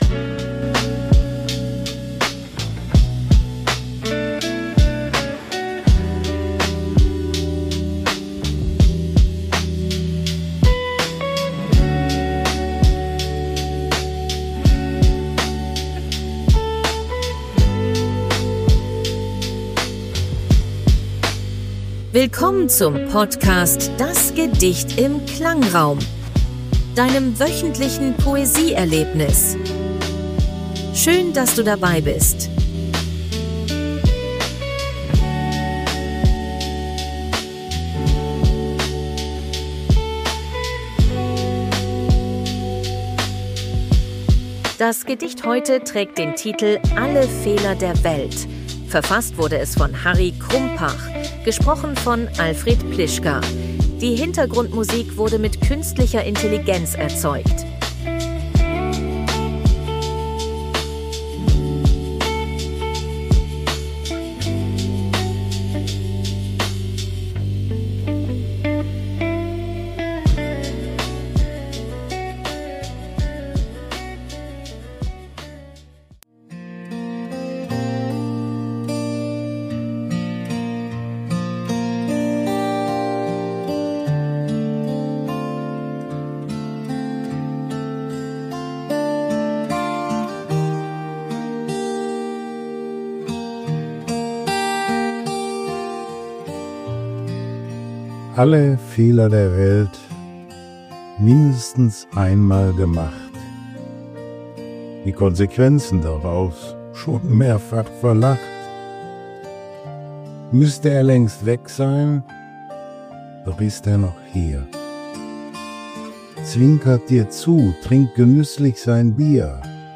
Die Hintergrundmusik